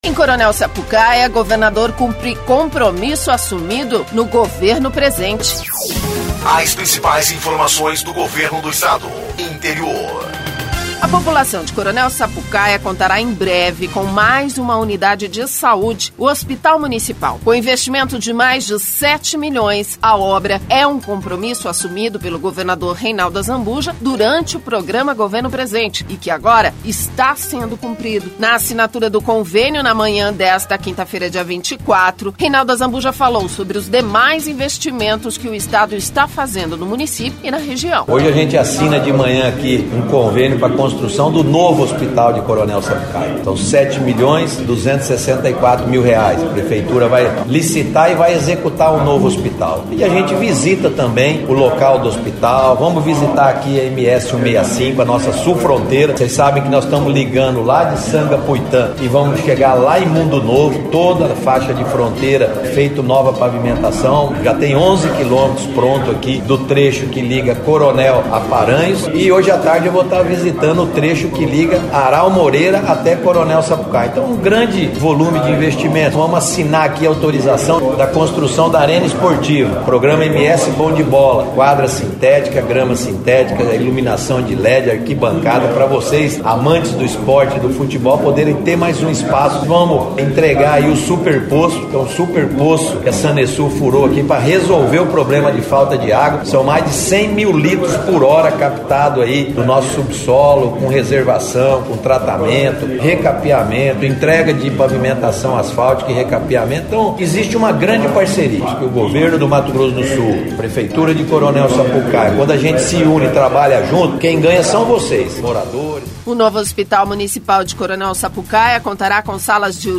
Durante a assinatura do convênio para o repasse, ma manhã desta quinta-feira, dia 24, Reinaldo falou sobre os demais investimentos que o Estado está fazendo no município e na região.